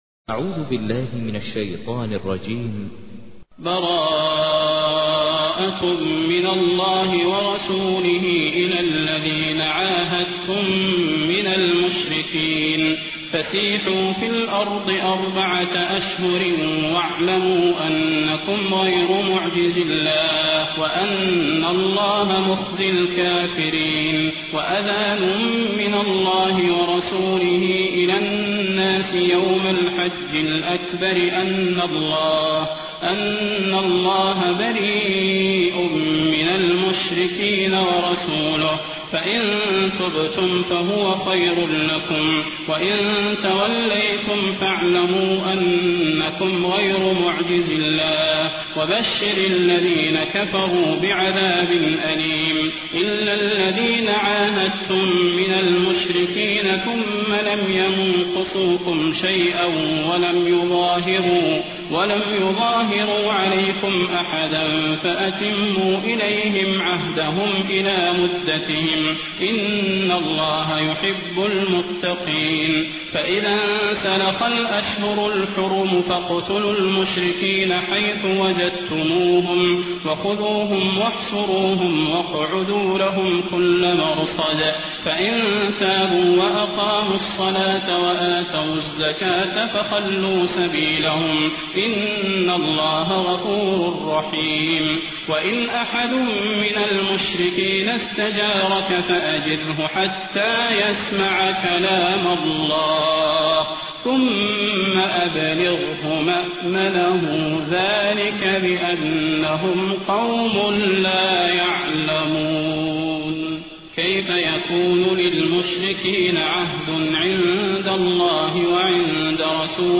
تحميل : 9. سورة التوبة / القارئ ماهر المعيقلي / القرآن الكريم / موقع يا حسين